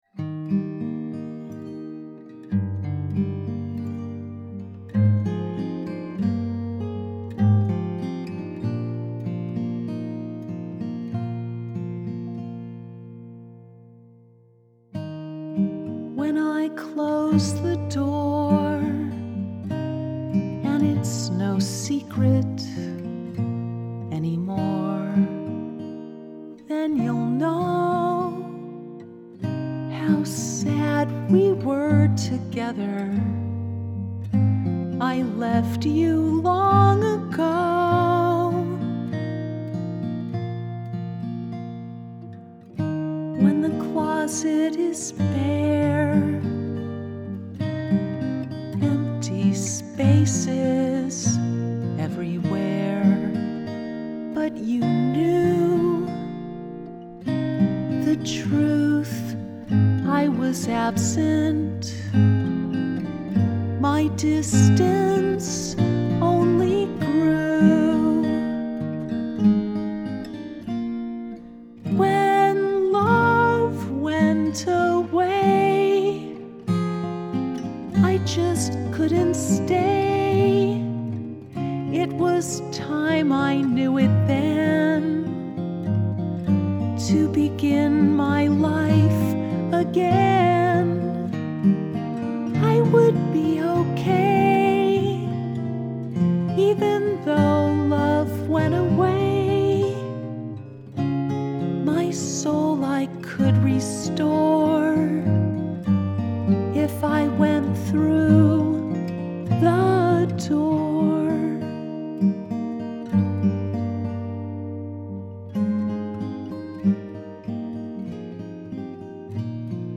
The Door and my guitar
The Door Acoustic 5-16-18
the-door-acoustic-5-16-18.mp3